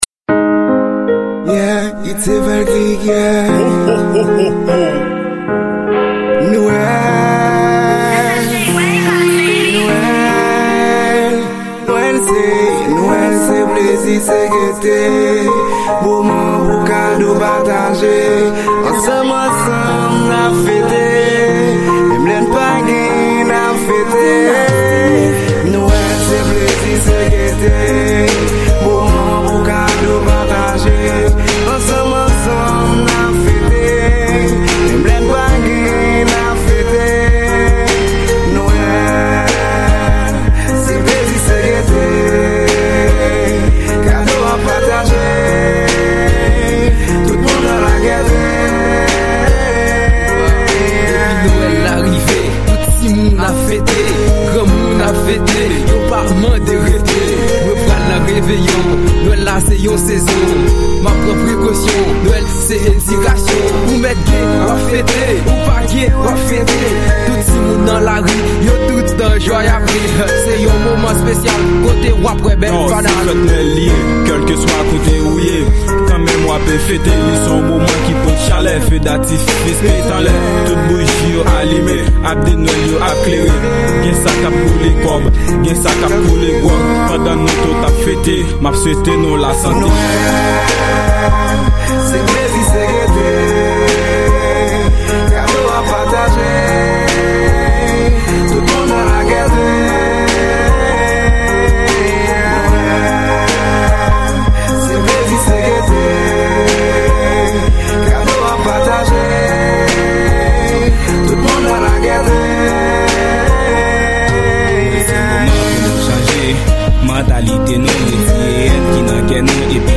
Genre:Rap